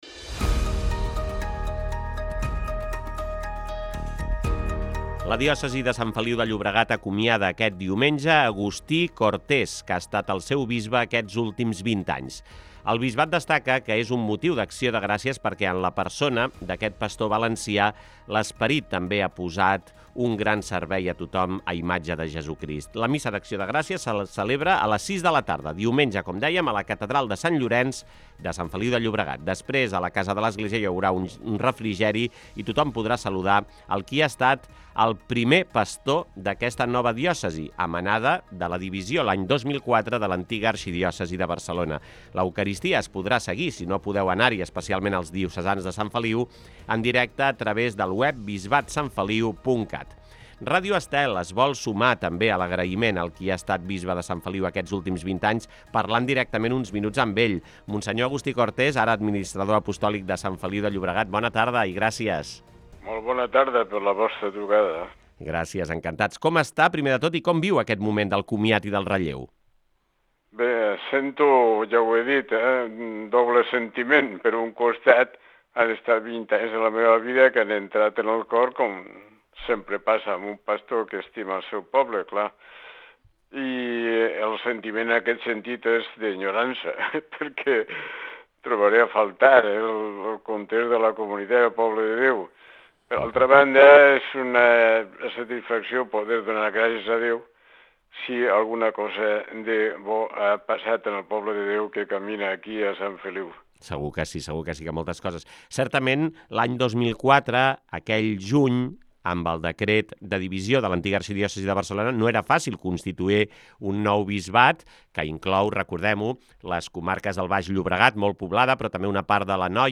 Entrevista a Agustí Cortés, bisbe sortint de Sant Feliu